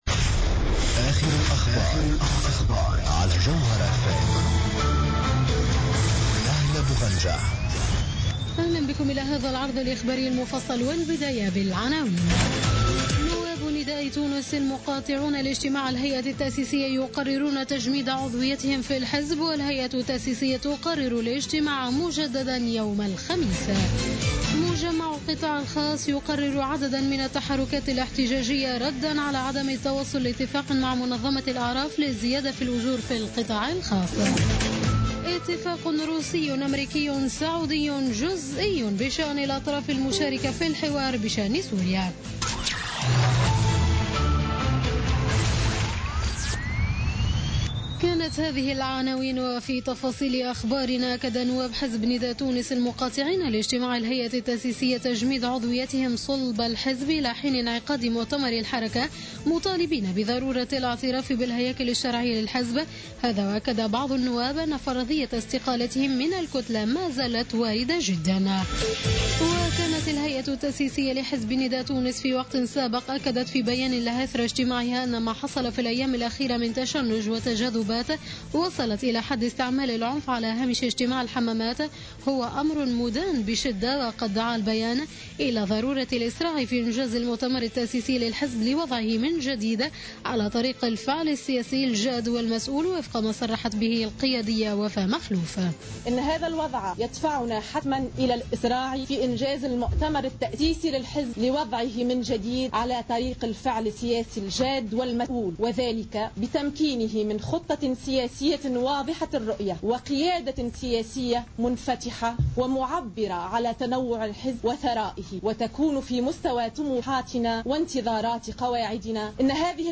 نشرة أخبار منتصف الليل ليوم الاربعاء 04 نوفمبر 2015